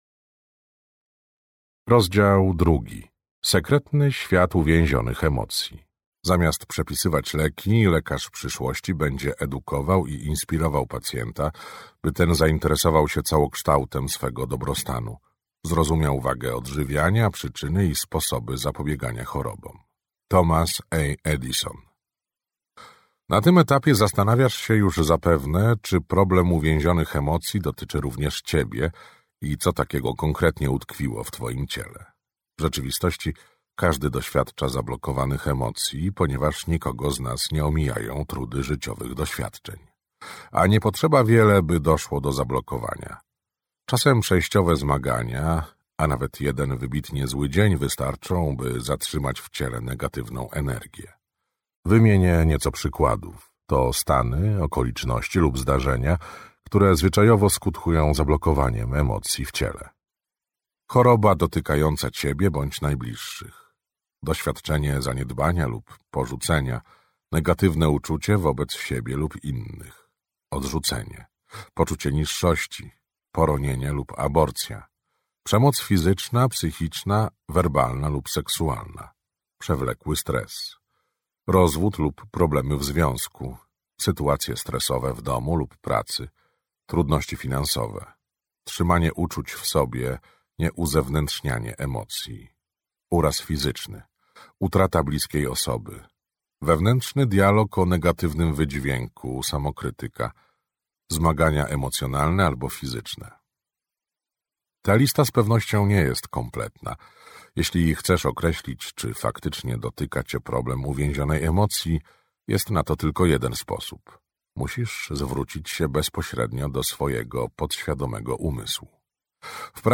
Kod emocji. Jak uwolnić zablokowane emocje i cieszyć się zdrowiem, szczęściem oraz miłością - Bradley Nelson - audiobook